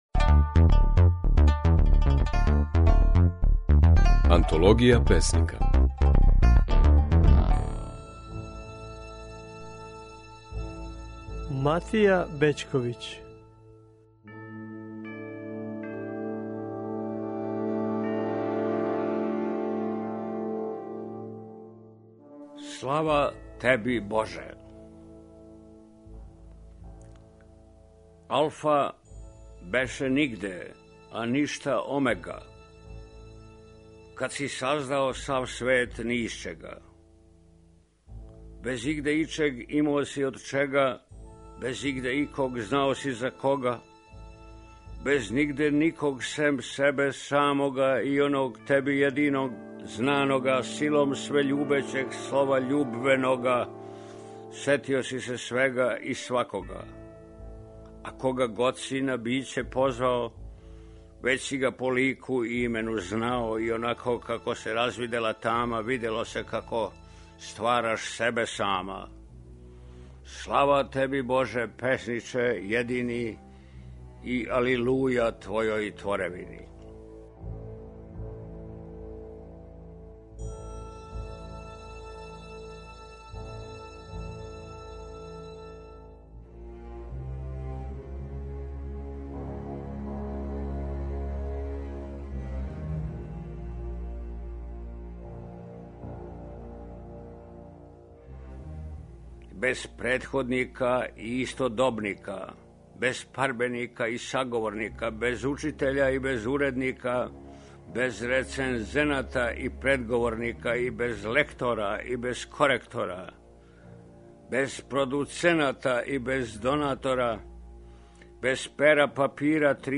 Можете чути како своје стихове говори Матија Бећковић, српски писац, песник и академик.
Емитујемо снимке на којима своје стихове говоре наши познати песници